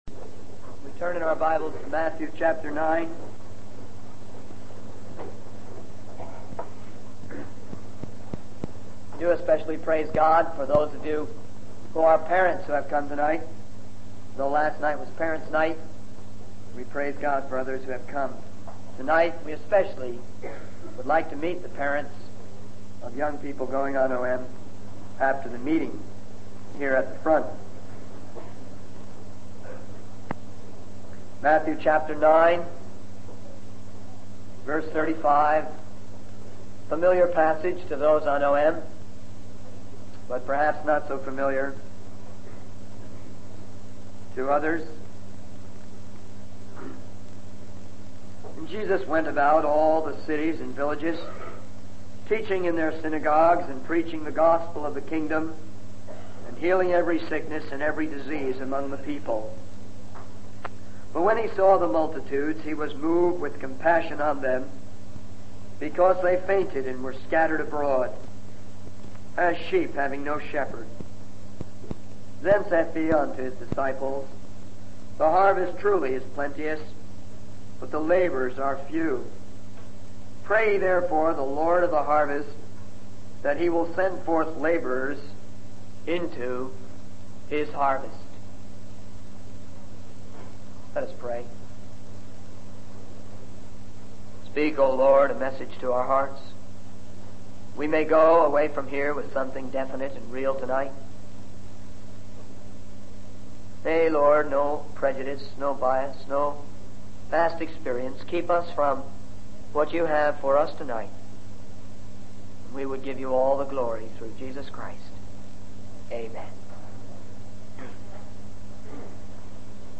In this sermon, the speaker reflects on the twisted state of the world and the media's portrayal of it. He emphasizes the contrast between the negative aspects of society and the positive experiences at a conference where people of different races and backgrounds come together in harmony. The speaker criticizes modern music as a medium that goes beyond communication and influences young people to engage in immoral behavior.